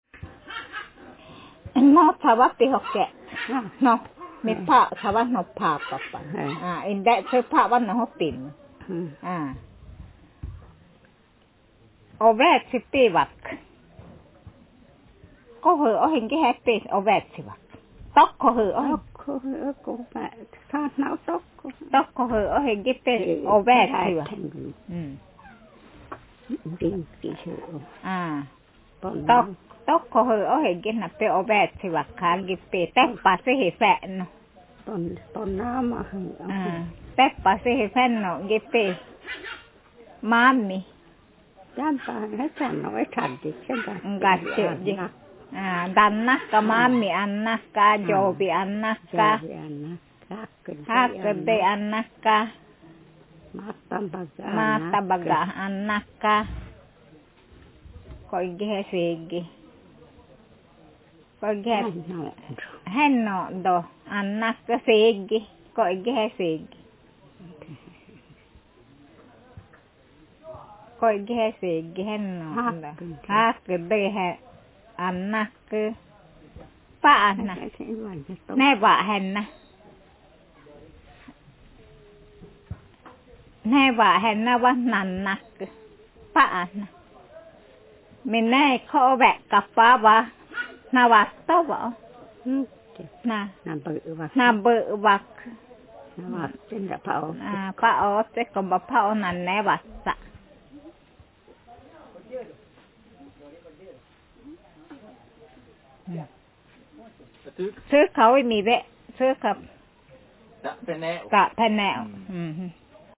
Speaker sexf/f
Text genreconversation